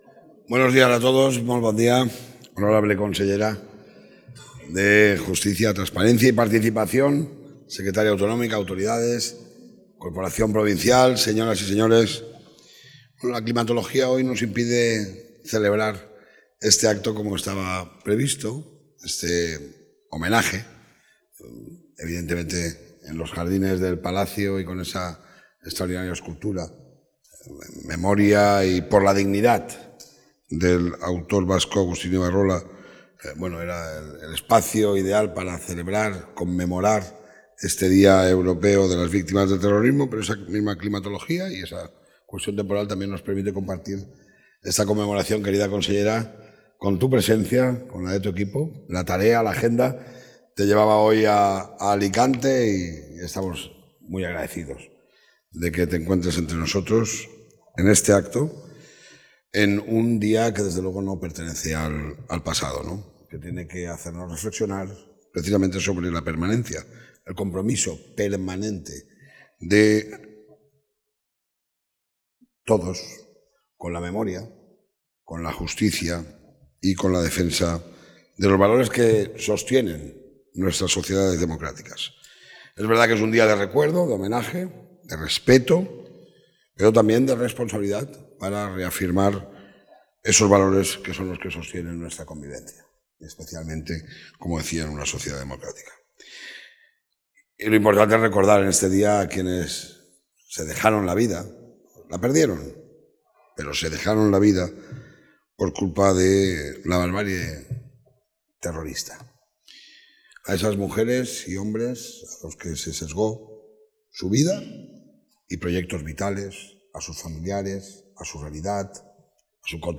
El presidente de la Diputación de Alicante participa en los actos conmemorativos del ‘Día Europeo de las Víctimas del Terrorismo’
Este homenaje a las víctimas del terrorismo, como símbolo de memoria y dignidad, ha contado con la lectura de un manifiesto institucional por parte de la consellera.